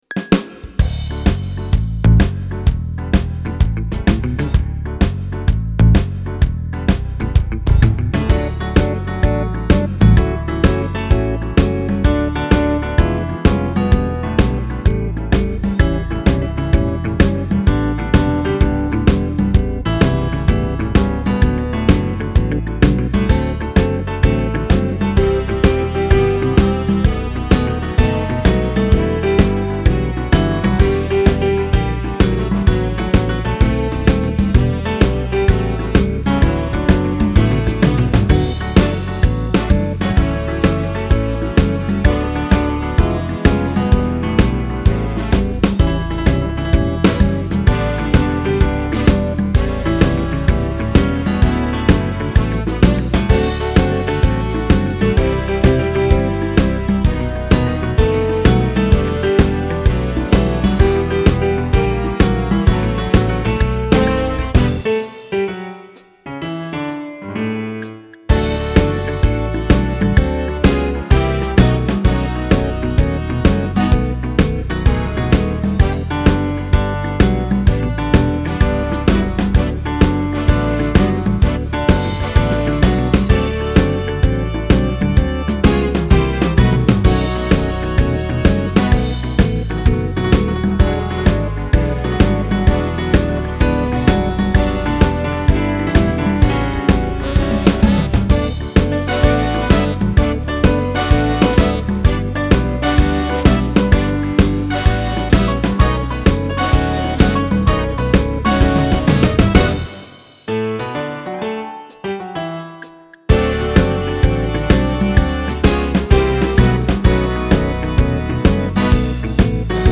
rock/swing/jazz combination